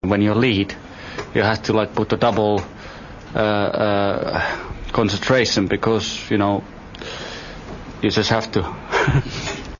Mika talking to the press